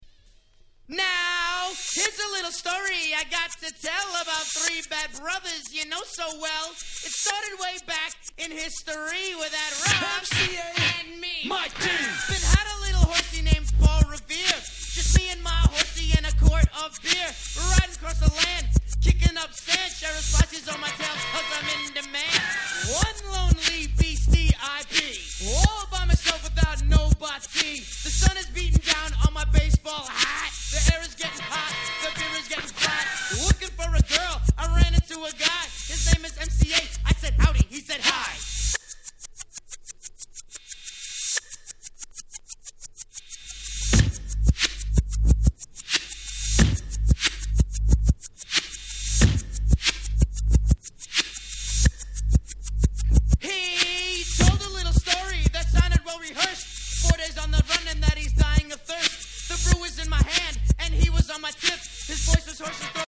Rock調の楽曲あり、ClassicなOld Schoolありの飽きの来ない最強の名盤！！